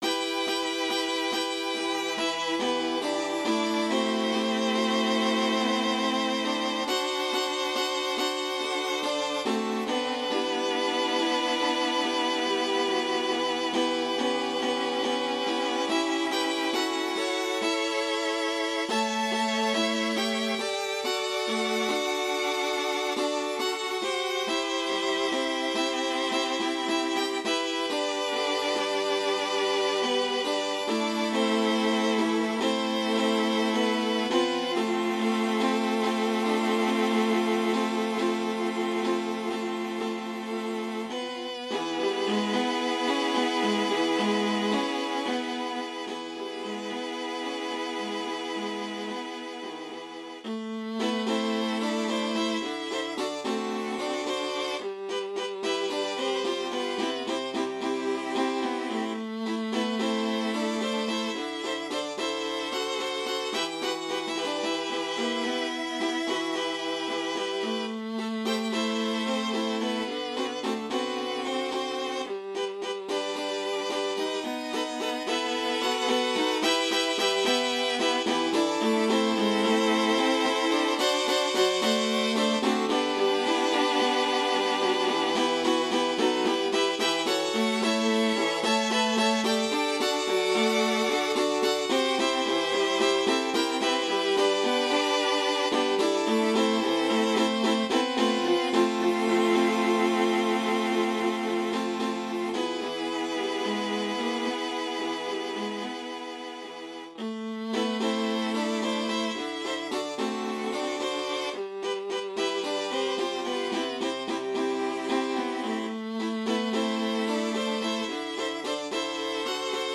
5-8 altviolen